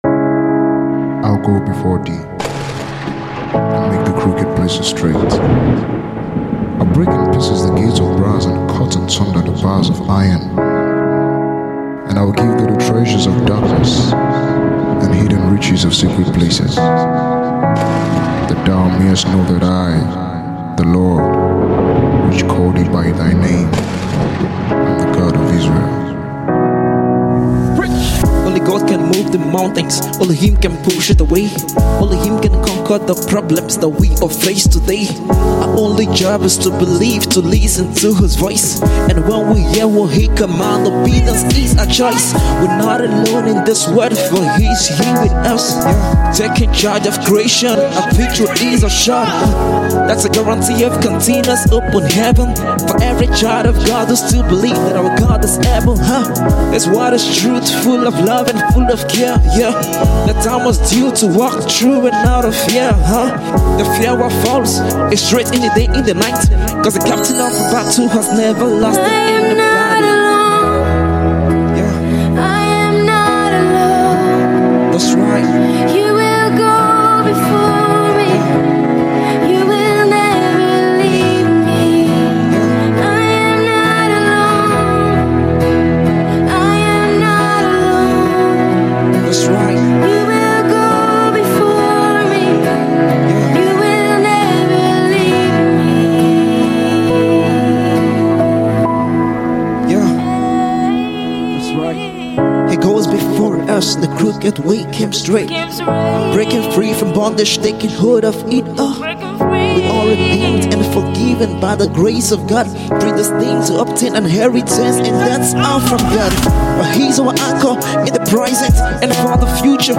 gospel music band
This  new song is a cover